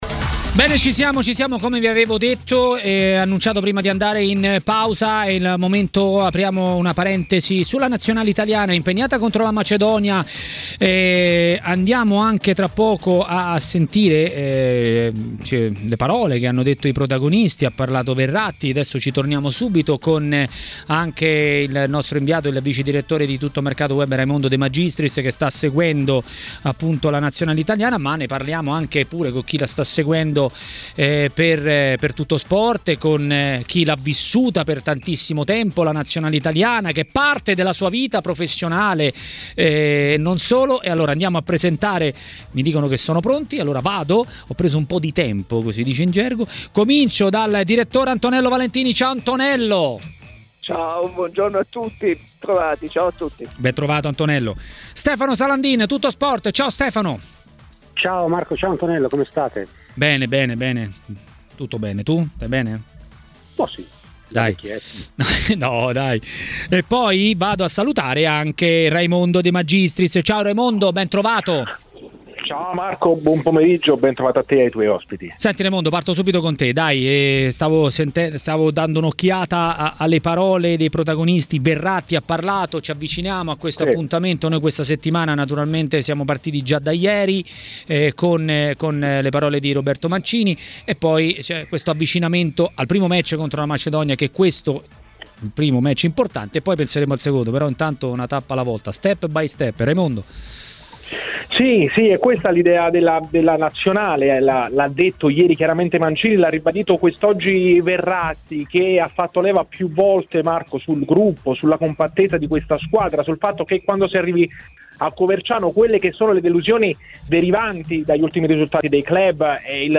è intervenuto durante la trasmissione Maracanà a TMW Radio per parlare della Nazionale.